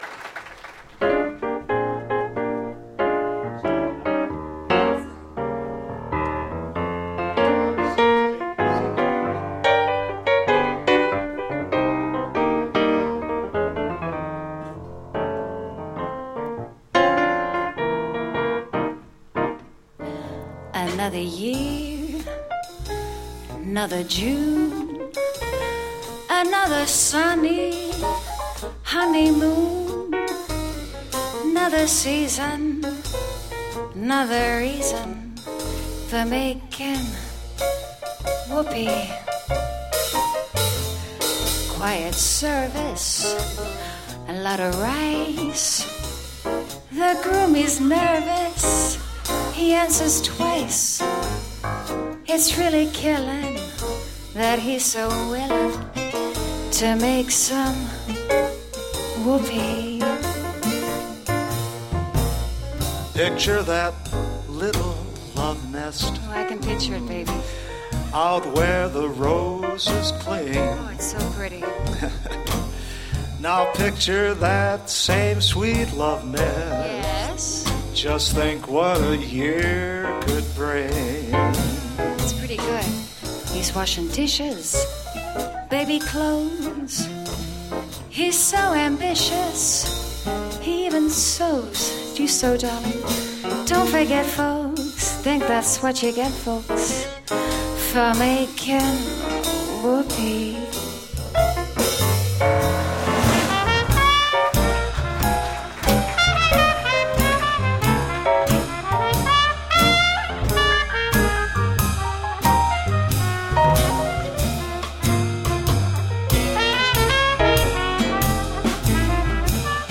double bass & vocals
clarinet
vibes